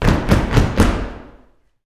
YOUTHFEET3-L.wav